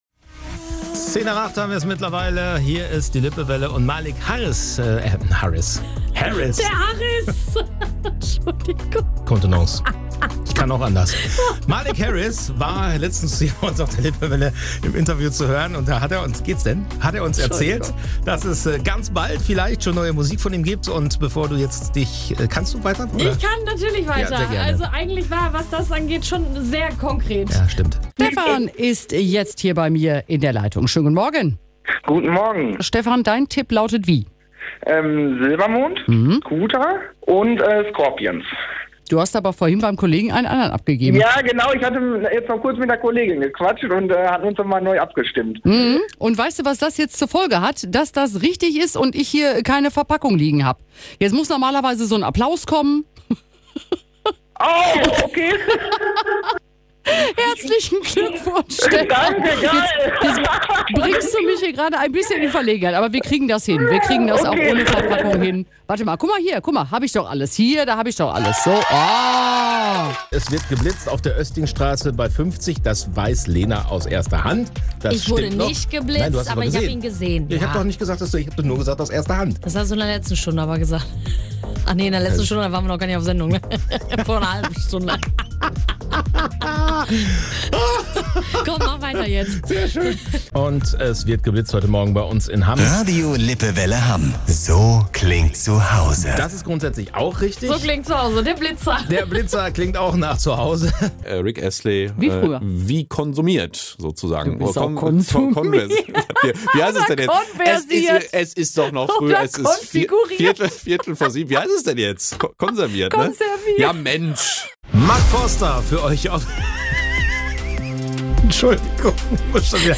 Pannen_Momente_Radio_Lippewelle_Hamm_2023.mp3